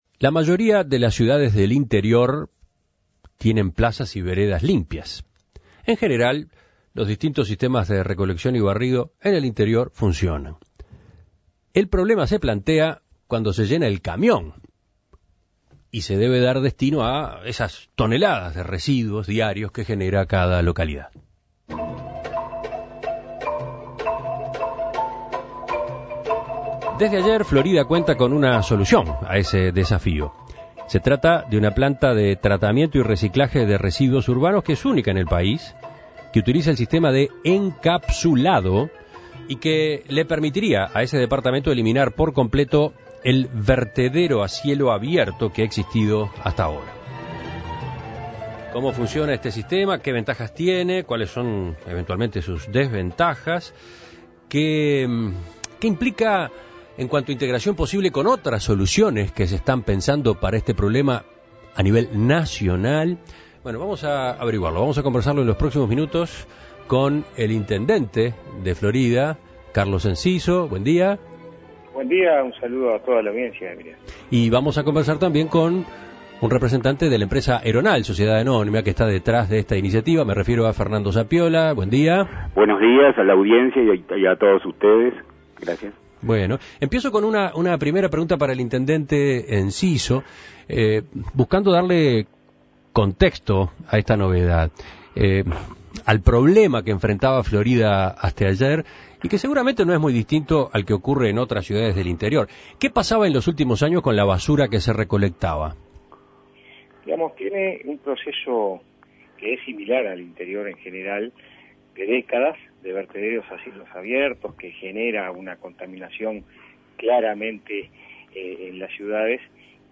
Para conocer más sobre esta novedad, En Perspectiva dialogó con el intendente de Florida, Carlos Enciso